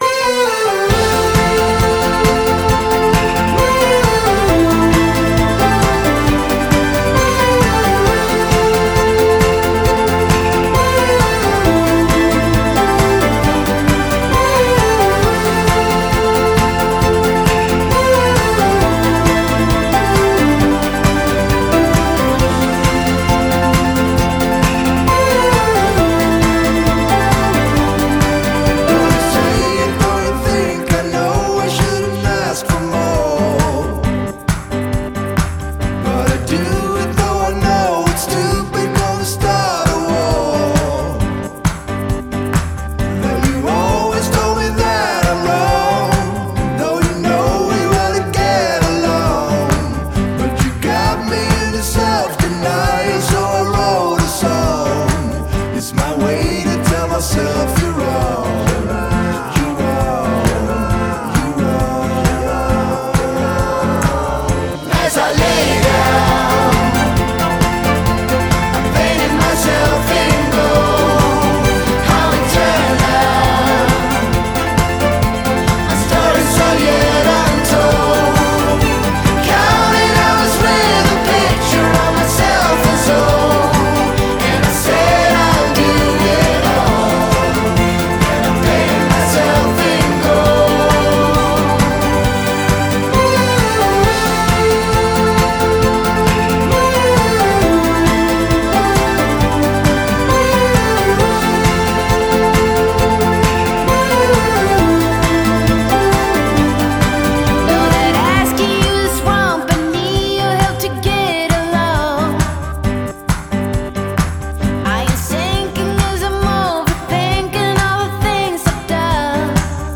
Genre: Indie-Pop / Folk